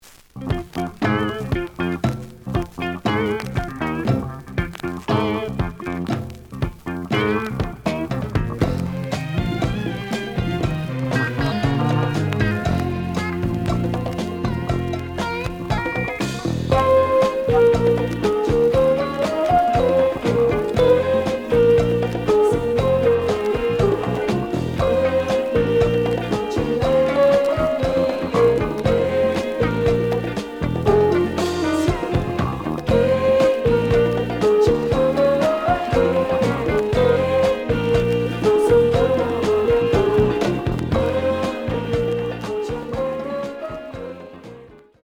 The audio sample is recorded from the actual item.
●Genre: Disco
Slight noise on B side.